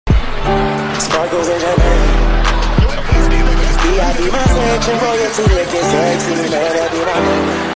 🤣🤣🤣🤣🤣🤣 Sound Effects Free Download.